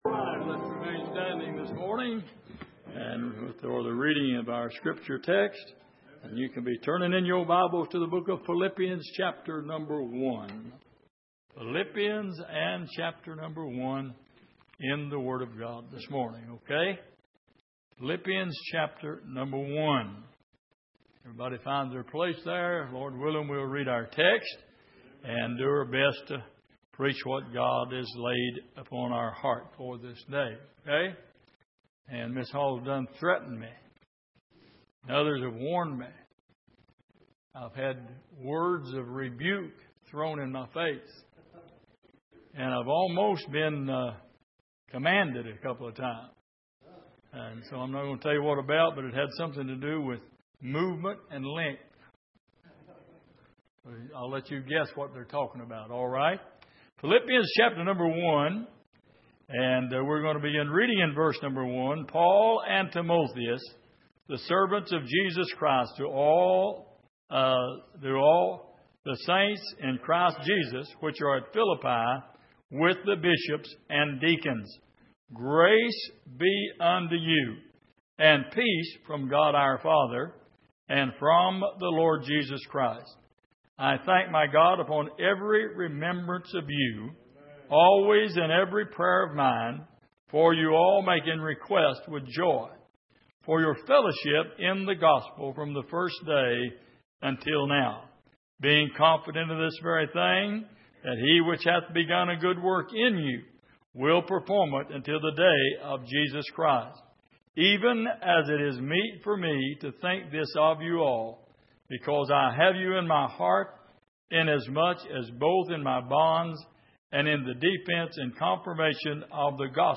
Miscellaneous Passage: Philippians 1:1-7 Service: Sunday Morning He’s Still Working On Me « Are All The Children Home?